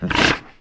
c_goril_hit3.wav